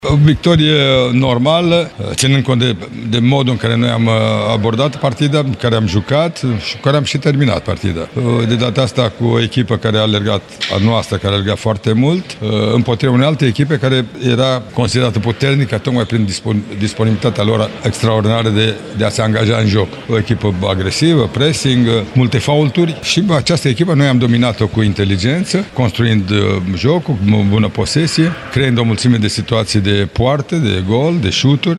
Selecționerul Mircea Lucescu s-a declarat mulțumit de modul în care a arătat echipa: